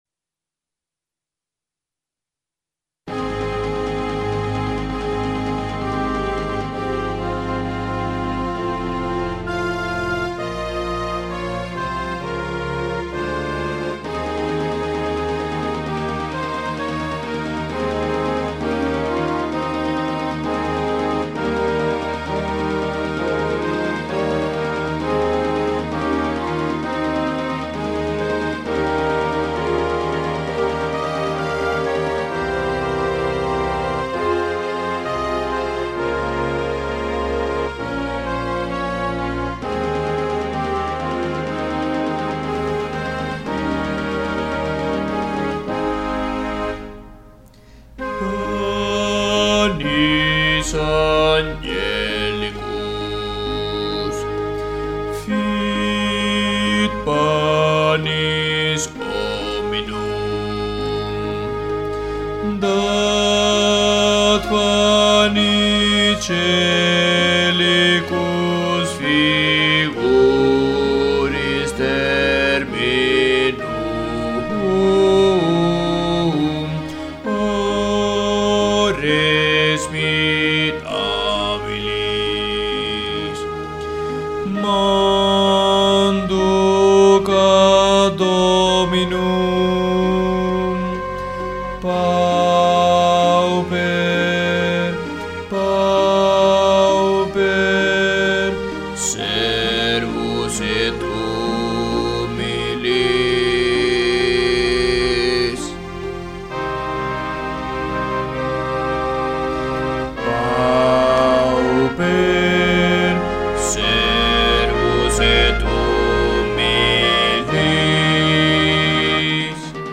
Musica SACRA Bajos
panis-angelicus-bajos.mp3